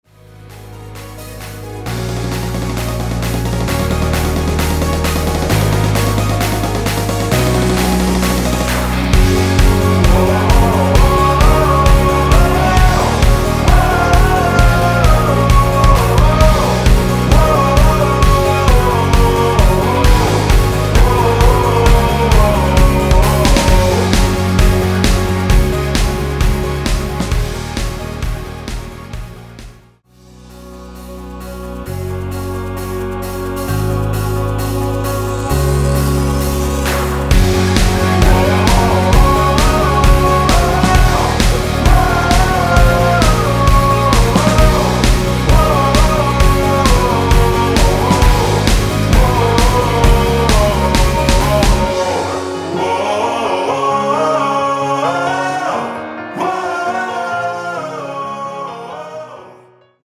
원키에서(-3)내린 코러스 포함된 MR입니다.(미리듣기 확인)
Gb
앞부분30초, 뒷부분30초씩 편집해서 올려 드리고 있습니다.
중간에 음이 끈어지고 다시 나오는 이유는